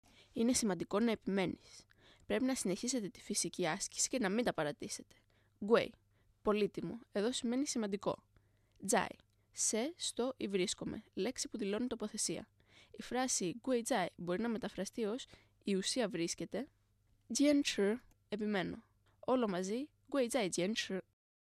贵在坚持。Guì zài jiānchí.